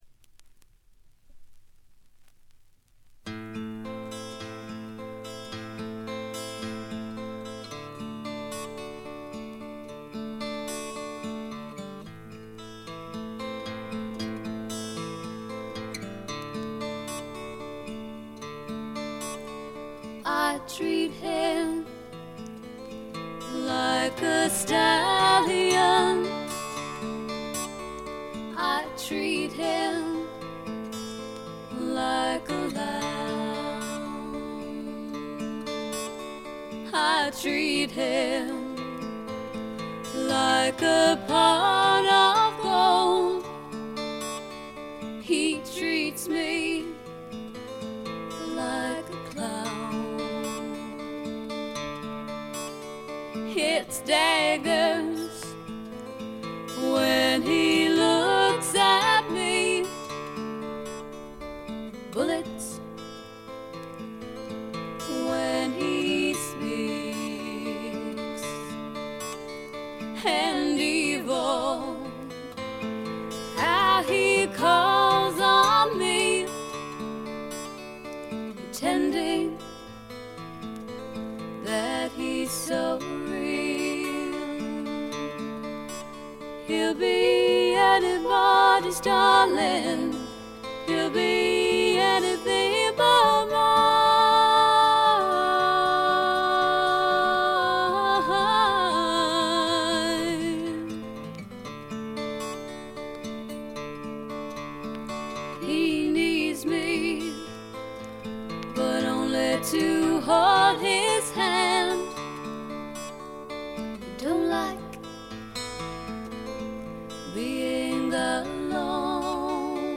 イングランドの小さなレーベルに残された女性シンガーの好盤です。
試聴曲は現品からの取り込み音源です。
Acoustic Guitar
Electric Guitar
Bass
Drums